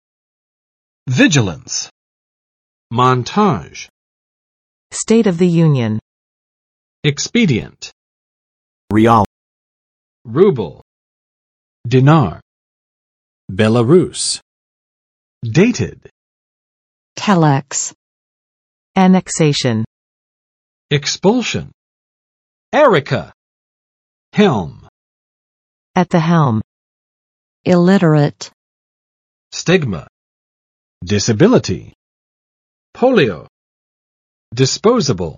[ˋvɪdʒələns] n. 警戒；警觉；警惕（性）